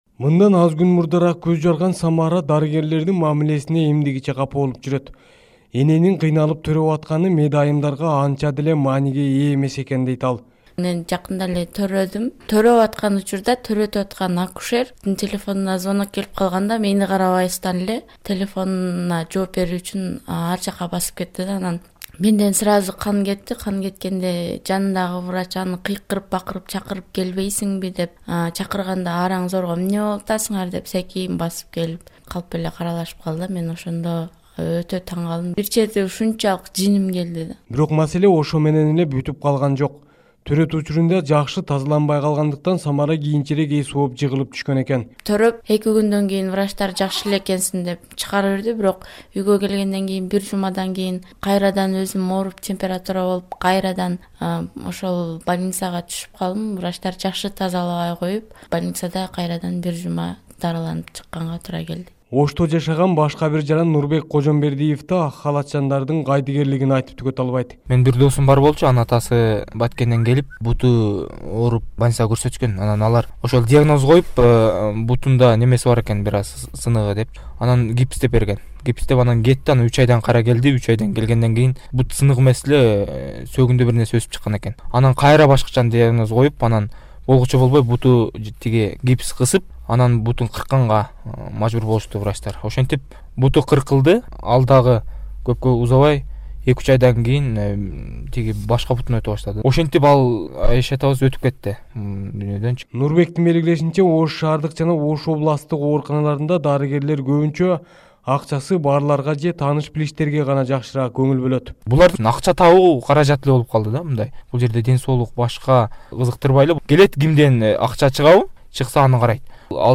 KYRGYZ/HEALTH/ Special Radio Package: Due to the negligence of doctors to their work patients also gets injured or suffered/ Report from southern Osh city